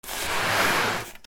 布ずれ（引きずる）
/ J｜フォーリー(布ずれ・動作) / J-05 ｜布ずれ